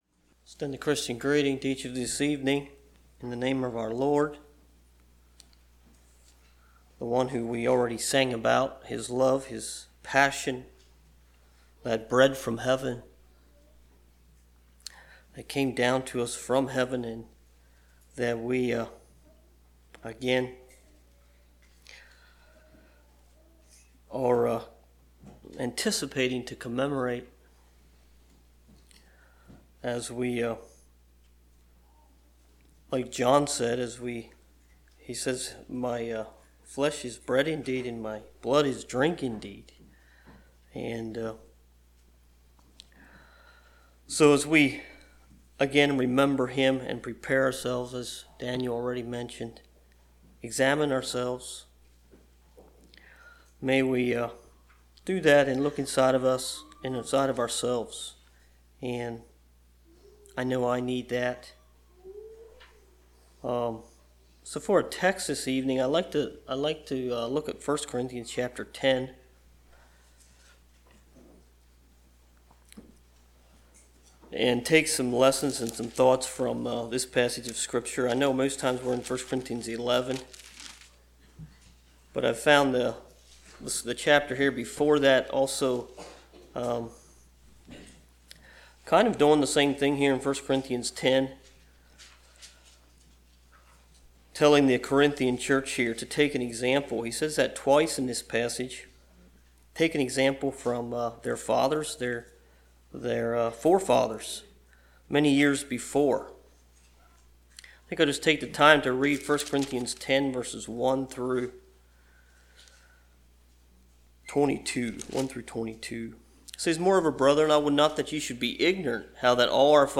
Passage: 1 Corinthians 10:1-29 Service Type: Wednesday Evening